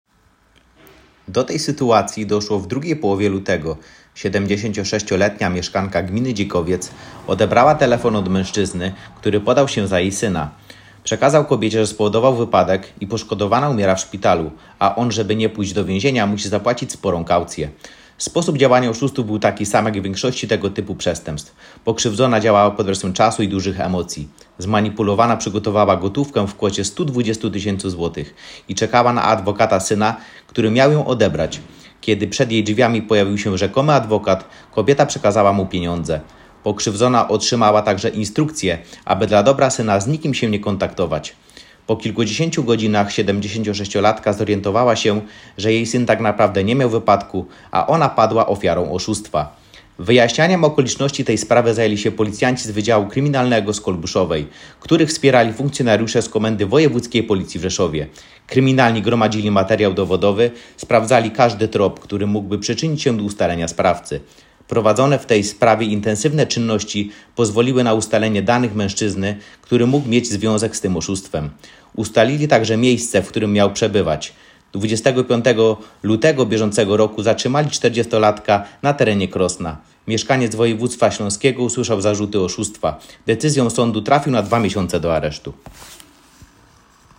Opis nagrania: Treść artykułu w formie dźwiękowej